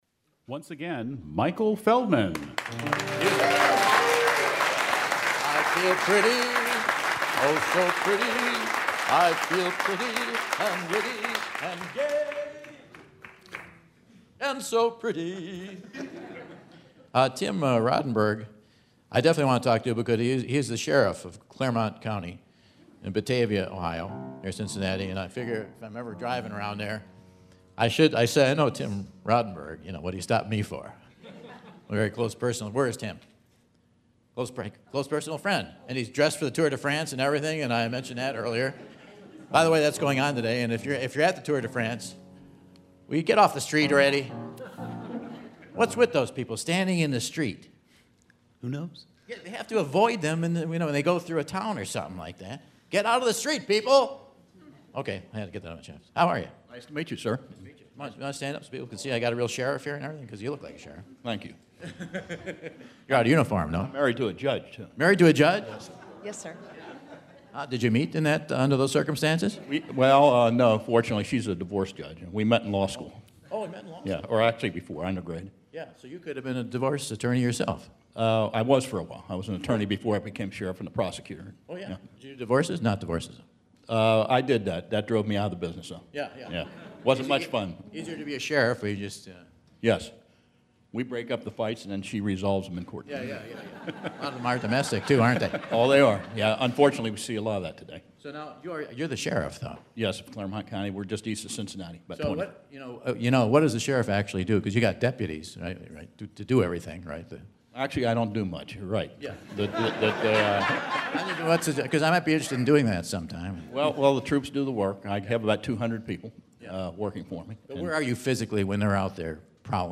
Two contestants won't need their shamrocks to play the Whad'Ya Know? Quiz!